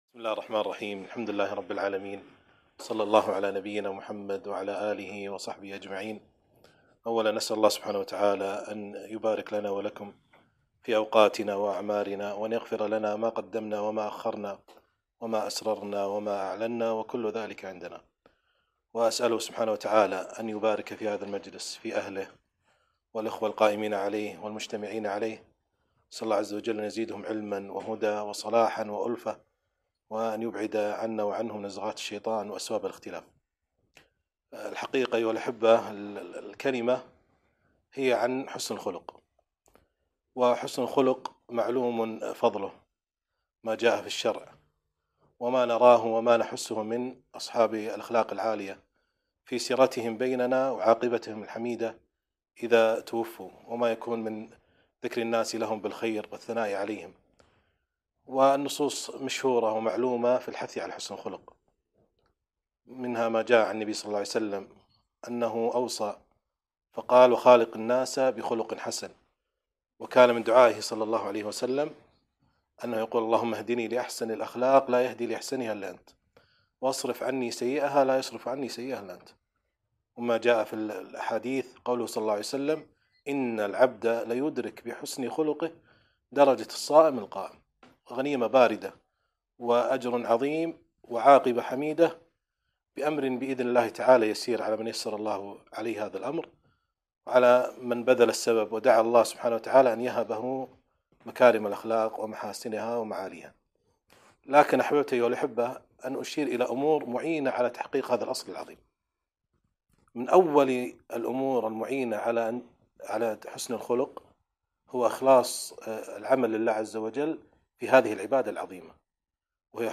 محاضرة بعنوان حسن الخلق